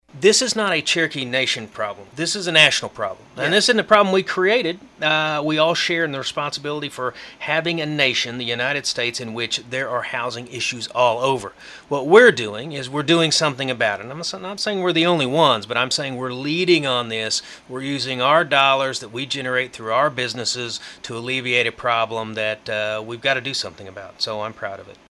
During a recent appearance on sister station KGGF in Coffeyville, Hoskin said the lack of housing can be considered a